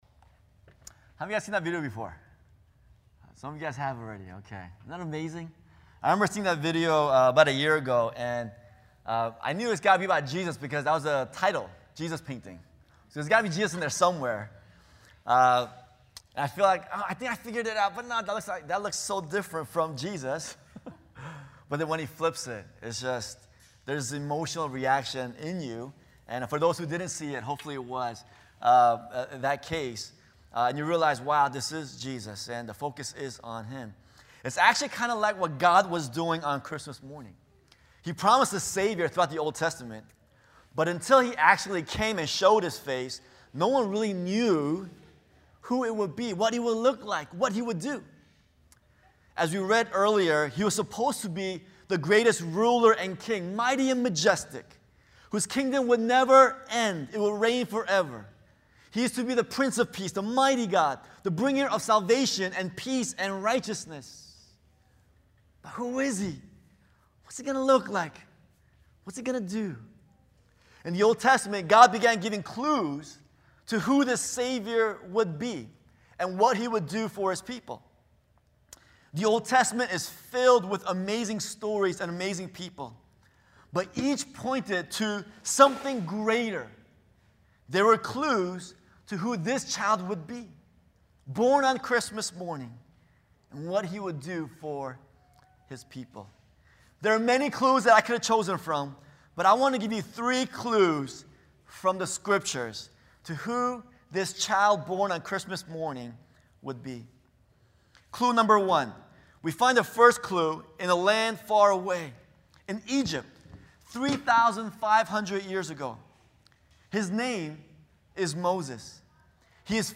sermons - Church of the Beloved